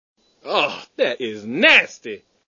Tags: ASA Sports announcing